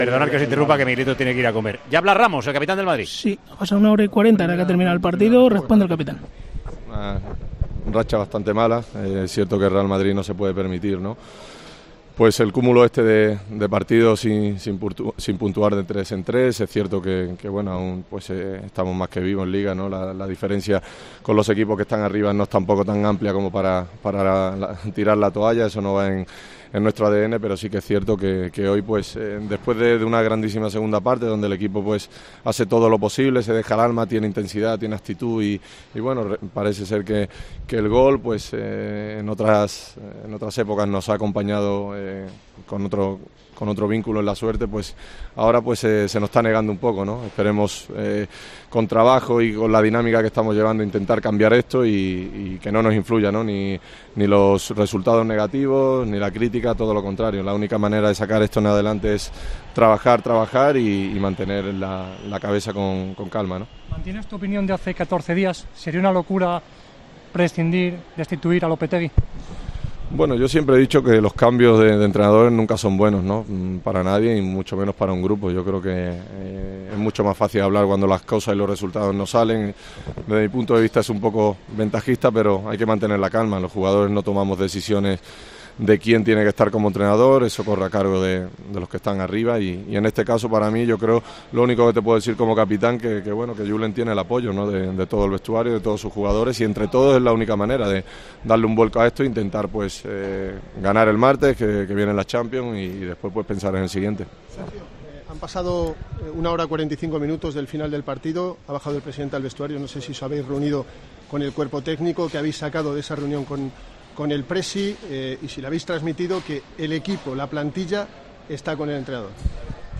Sergio Ramos atendió a los medios de comunicación tras agravarse la crisis del Real Madrid con la derrota (1-2) ante el Levante este sábado en el Santiago Bernabéu.